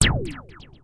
1 channel
battle_item_Spidrtrap_shoot.wav